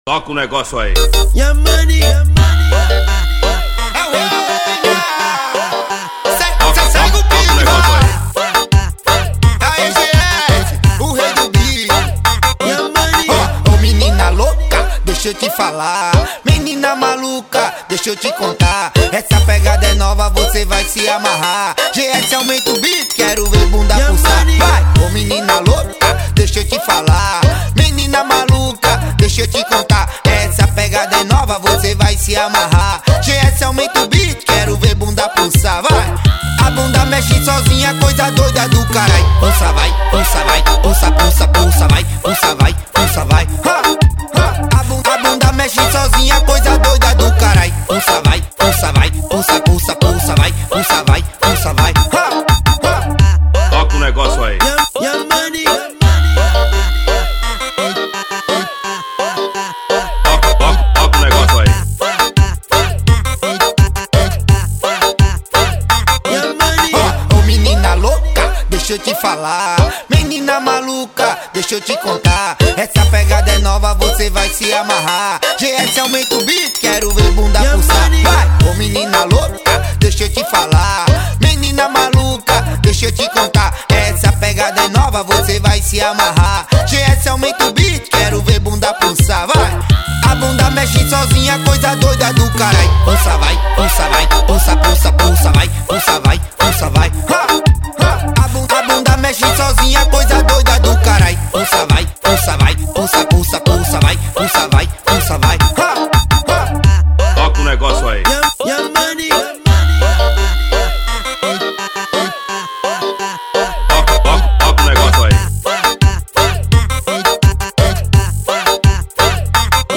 EstiloBrega Funk